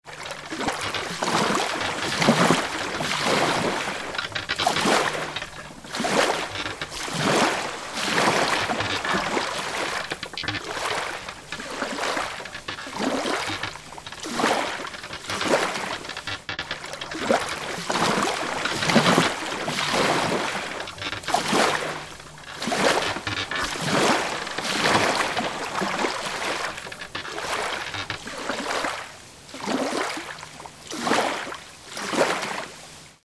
دانلود آهنگ دریا 11 از افکت صوتی طبیعت و محیط
دانلود صدای دریا 11 از ساعد نیوز با لینک مستقیم و کیفیت بالا
جلوه های صوتی